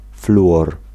Ääntäminen
Synonyymit difluor gaz fluor Ääntäminen France Tuntematon aksentti: IPA: /fly.ɔʁ/ Haettu sana löytyi näillä lähdekielillä: ranska Käännös Konteksti Ääninäyte Substantiivit 1. fluor {m} kemia Suku: m .